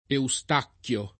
Eustachio [euSt#kLo] (region. Eustacchio [